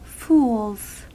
Ääntäminen
Ääntäminen US : IPA : [fuːlz] Haettu sana löytyi näillä lähdekielillä: englanti Käännöksiä ei löytynyt valitulle kohdekielelle.